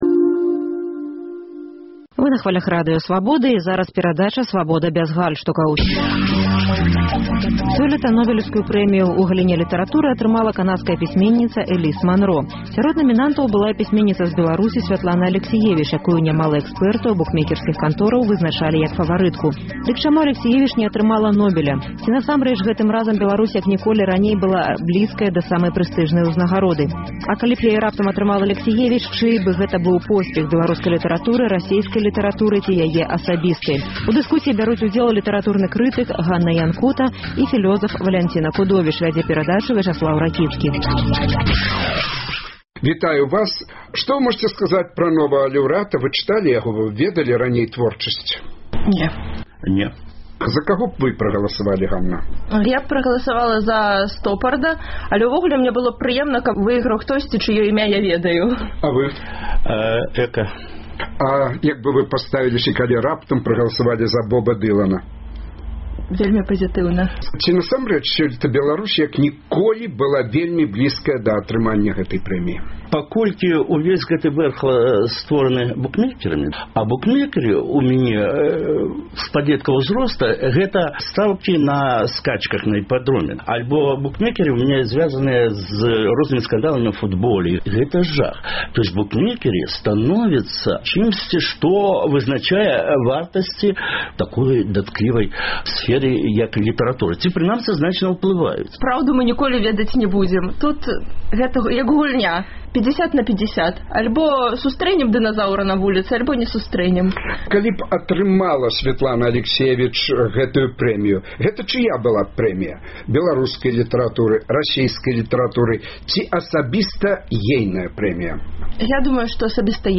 У дыскусіі